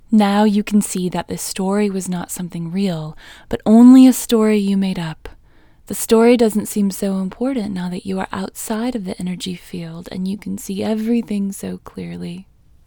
OUT Technique Female English 19